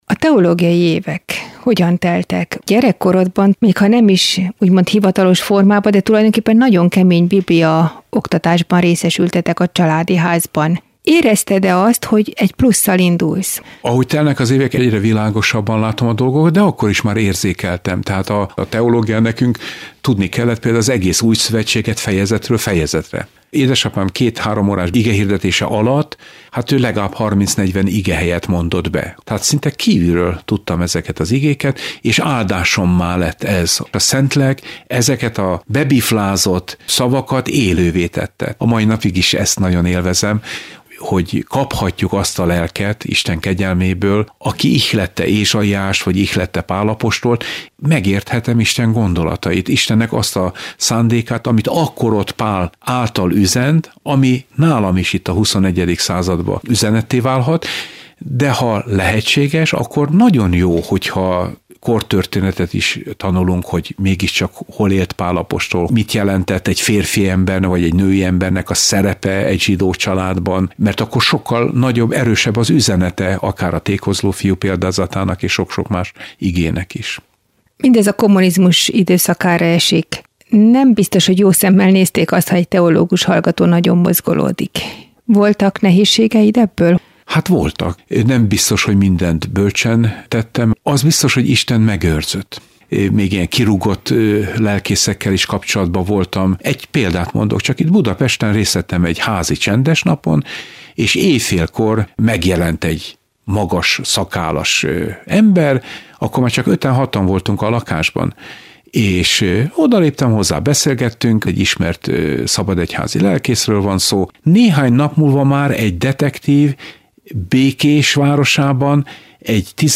Meghallgatás/letöltés (20 MB) Az interjú a Magyar Evangéliumi Rádóban (MERA) készült 3x25 percben, ez a teljes változat meghallgatható itt: